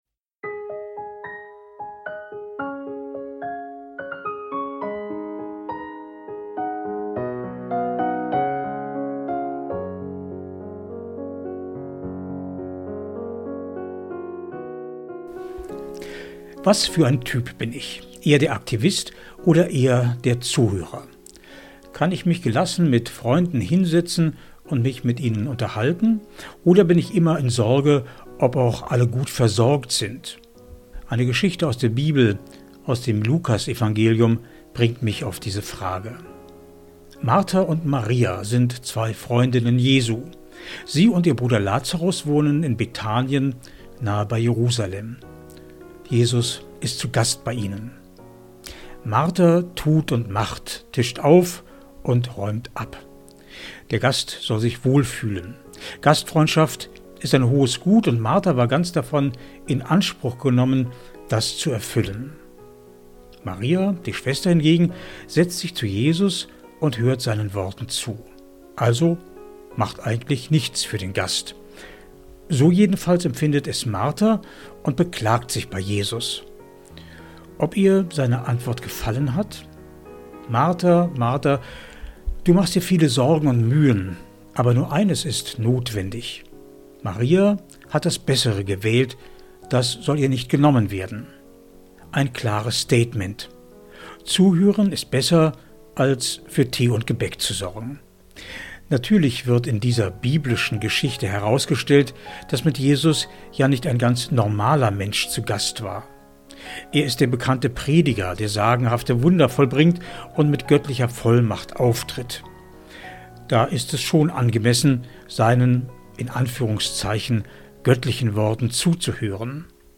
MEDITATION